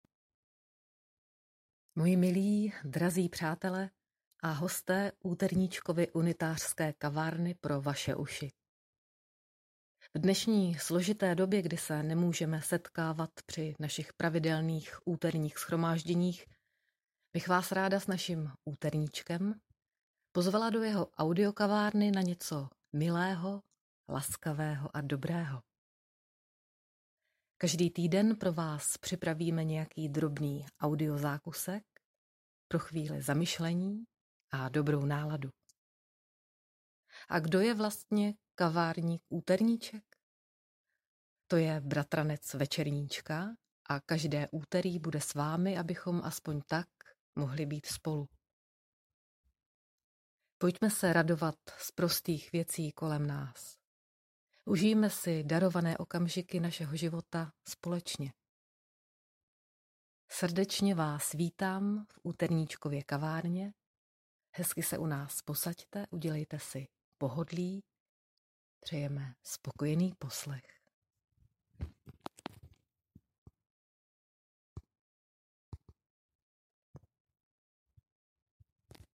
Všechno připravujeme bez kvalitní techniky, proto omluvte případný drobný šum v kvalitě nahrávky.